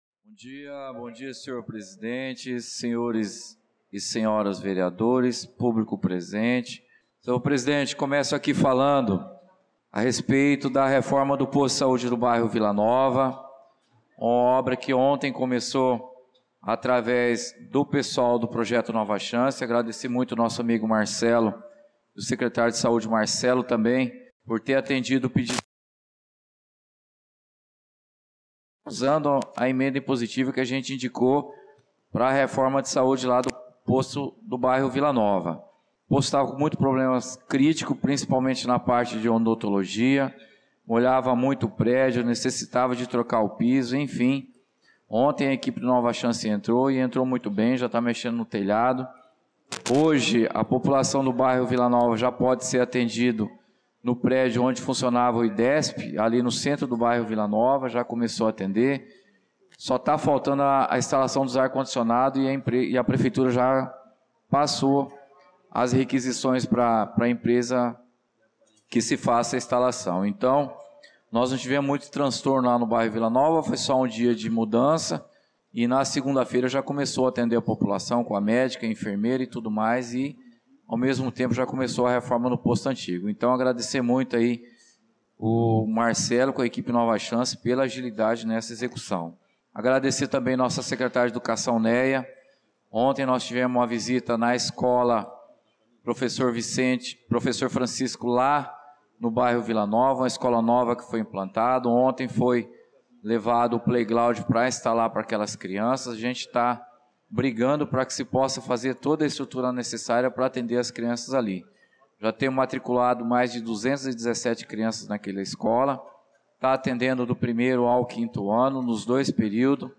Pronunciamento do vereador Claudinei de Jesus na Sessão Ordinária do dia 11/03/2025